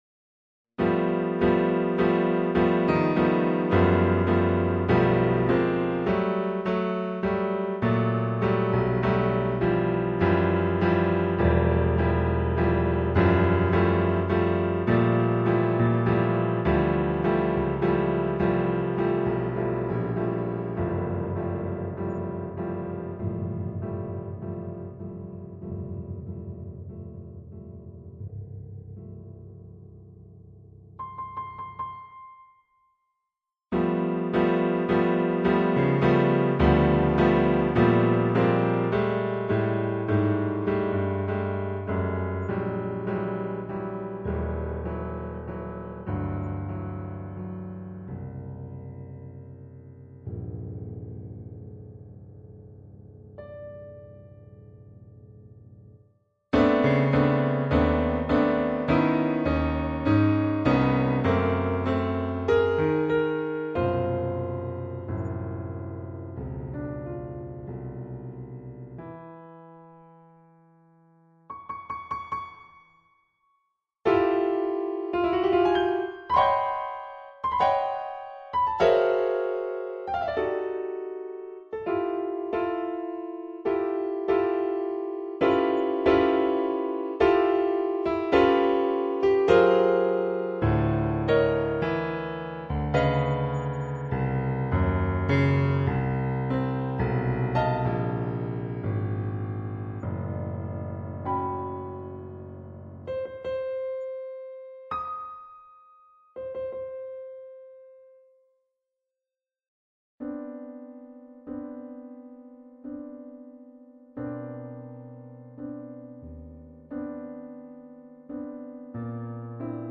Instrument(s): piano solo.